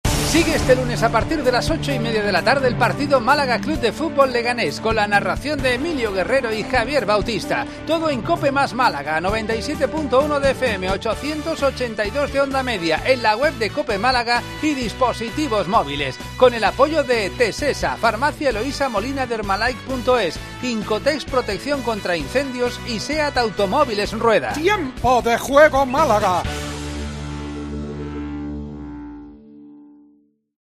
Promo partido